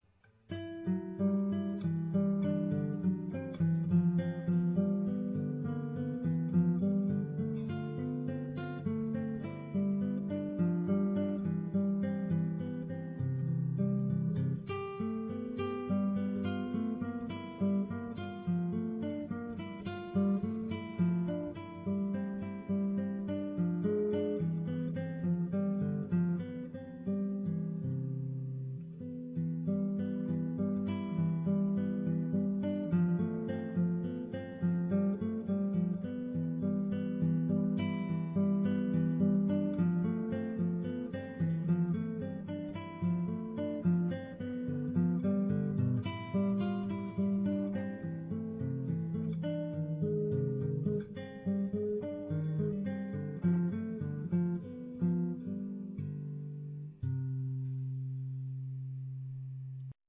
Tuning: EADGBE Key: C major Sample:
RealAudio Format (Myself playing)